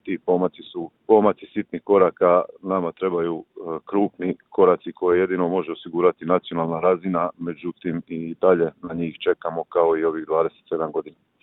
ZAGREB - Uoči obilježavanja vukovarske tragedije kratko smo razgovarali s gradonačelnikom Vukovara Ivanom Penavom.